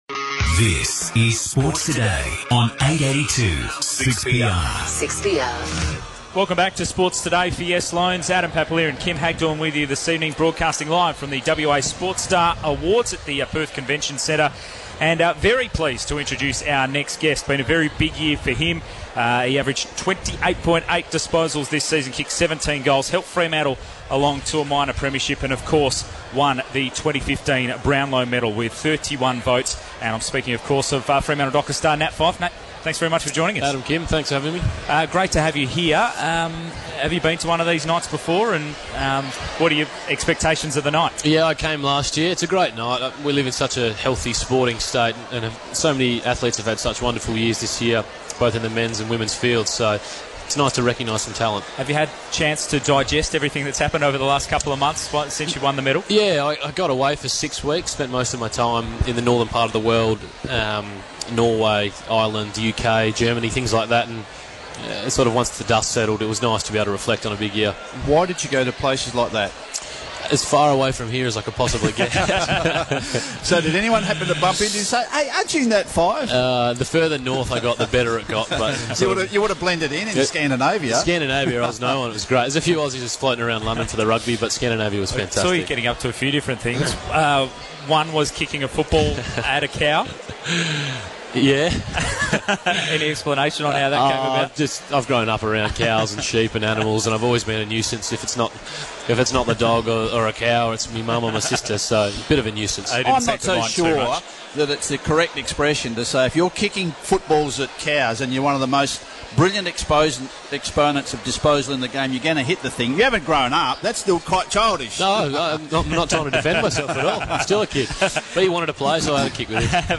Nat Fyfe spoke to 6PR at the Sports Star Awards